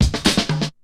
Drum roll 2.wav